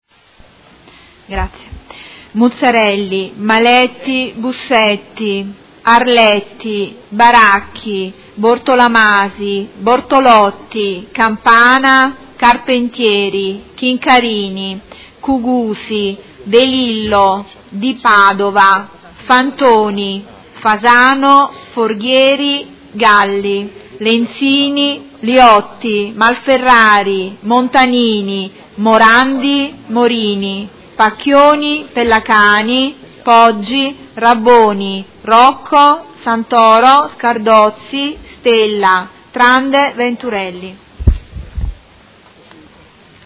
Seduta del 29/11/2018 Appello.
Segretaria